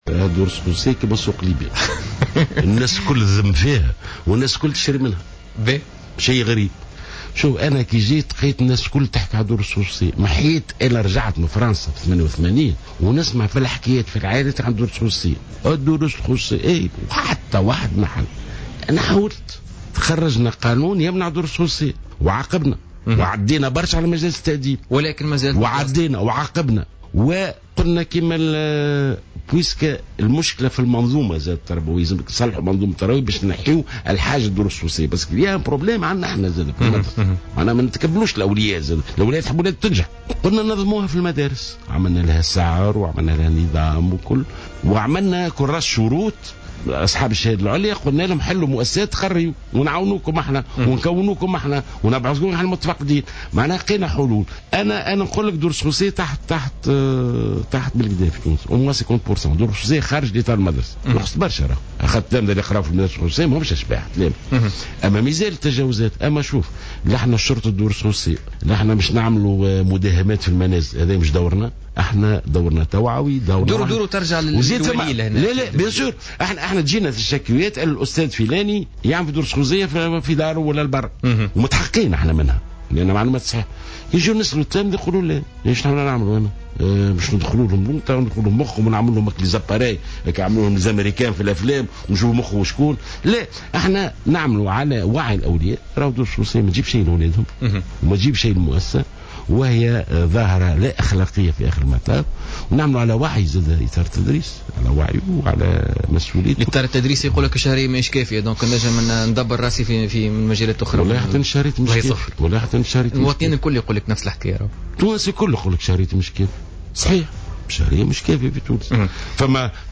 وصف وزير التربية ناجي جلول ضيف بوليتيكا اليوم الجمعة 22 أفريل 2016 أن الدروس الخصوصية ب"سوق ليبيا" الناس الكل تشكي منو لكن تشري منو..